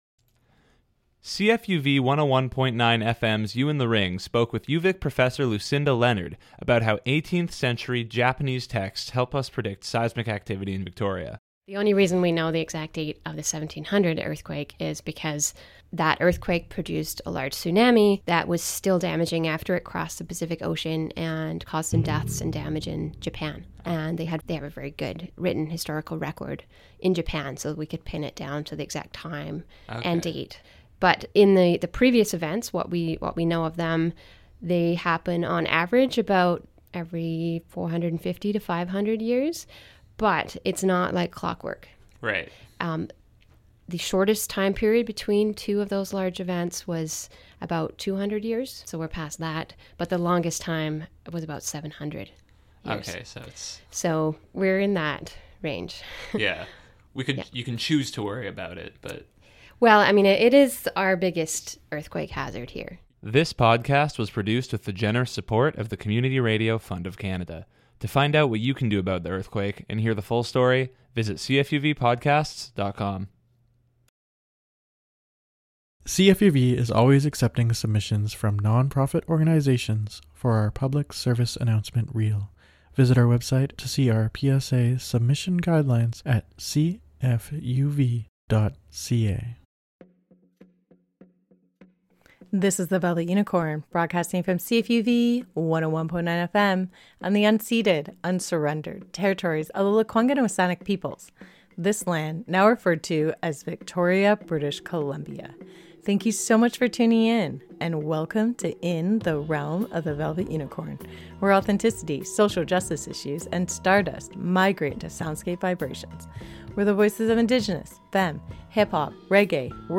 Where hip hop, electronica and world beats collide with social justice issues